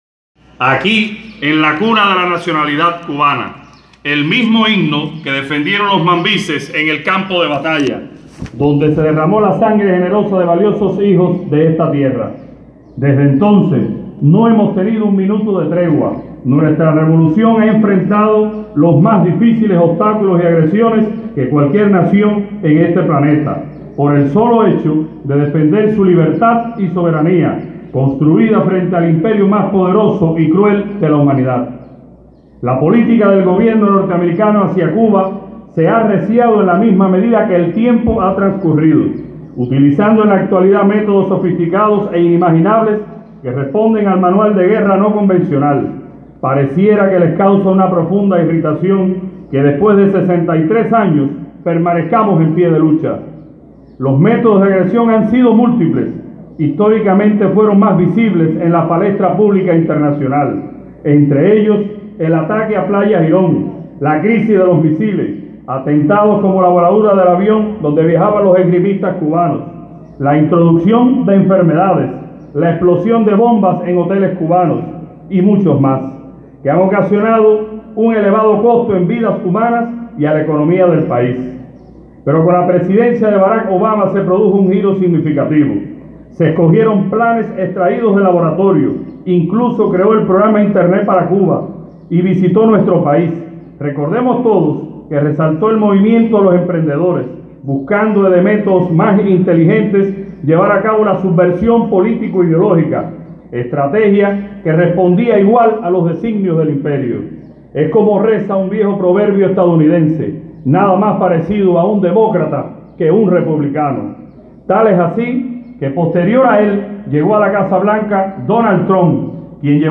En la simbólica Plaza del Himno, de Bayamo, donde se acrizoló la nacionalidad cubana, la voz del pueblo se levantó hoy entre consignas y vítores a la Patria para ratificar su compromiso de defender la Revolución cubana.
Palabras de Calixto Santiesteban, primer secretario del Partido en Bayamo